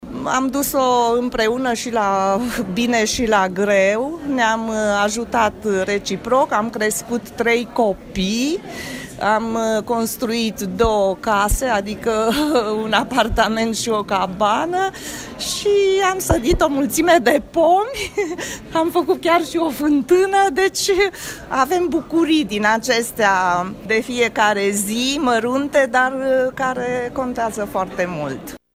Ieri, în cadrul Zilelor Târgumureșene, la Centrul Cultural Mihai Eminescu din Tîrgu Mureș a avut loc încă o ediție a întâlnirii “Nunta de Aur”, dedicată cuplurilor de târgumureșeni care au împlinit 50 de ani de căsătorie, împreună.